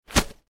دانلود آهنگ تصادف 2 از افکت صوتی حمل و نقل
جلوه های صوتی
دانلود صدای تصادف 2 از ساعد نیوز با لینک مستقیم و کیفیت بالا